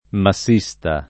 vai all'elenco alfabetico delle voci ingrandisci il carattere 100% rimpicciolisci il carattere stampa invia tramite posta elettronica codividi su Facebook massista [ ma SS&S ta ] (meno com. masista ) s. m. (mar.); pl.